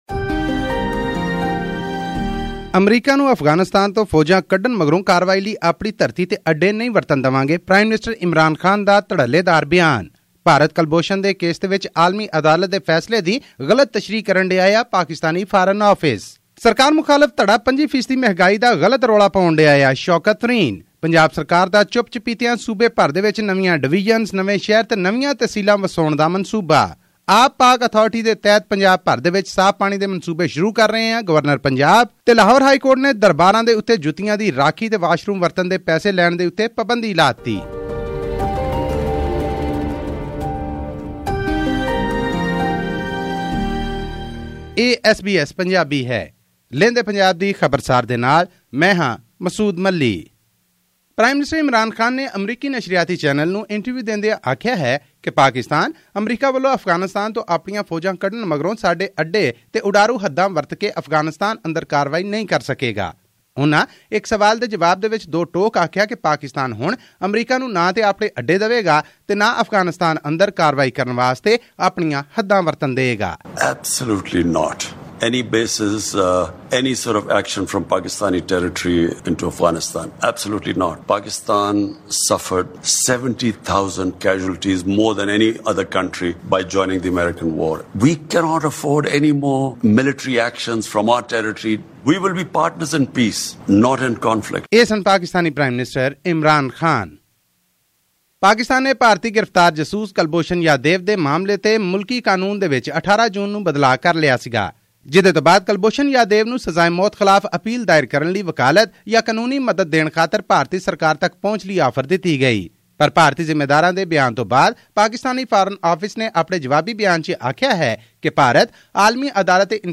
Prime Minister Imran Khan says that his country is ready to partner with the United States in peace efforts in Afghanistan, but it will not host American bases on its soil for any counterterrorism operations. All this and more in our news bulletin from Pakistan.